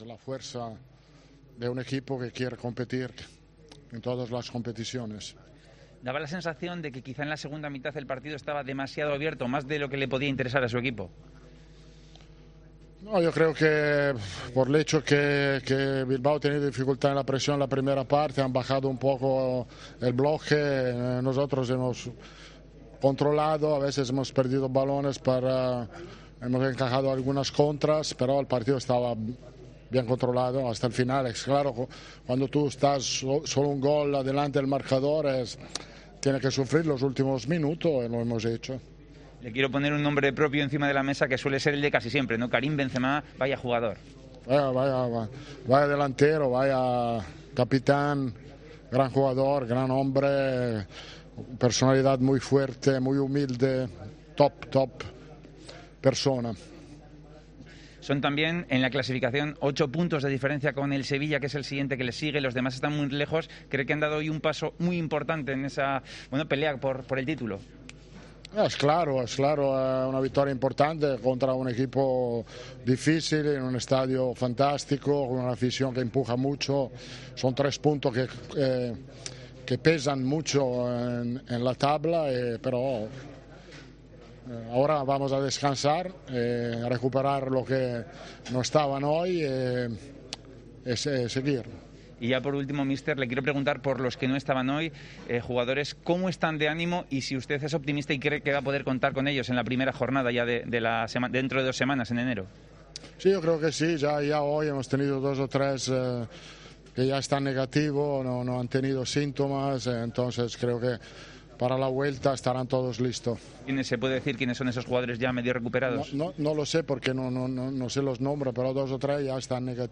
El técnico del Real Madrid estuvo en el micrófono de Movistar tras la victoria de su equipo ante el Athletic.